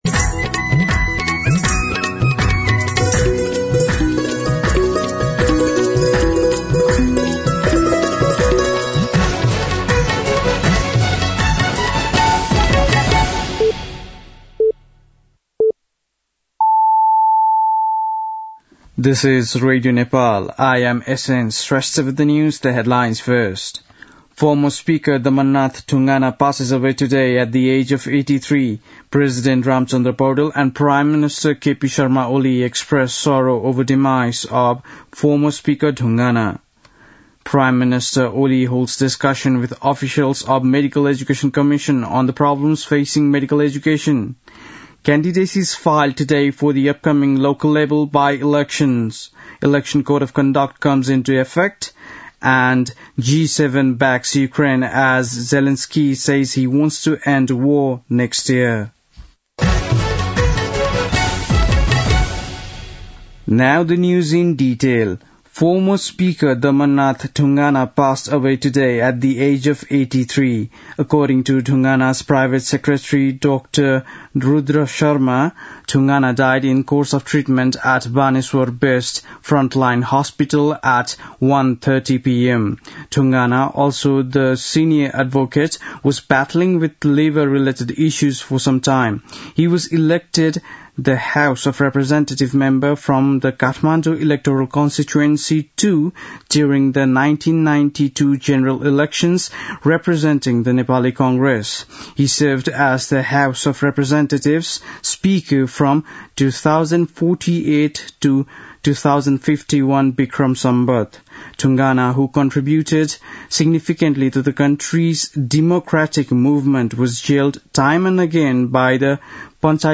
बेलुकी ८ बजेको अङ्ग्रेजी समाचार : ३ मंसिर , २०८१
8-PM-English-News-8-2.mp3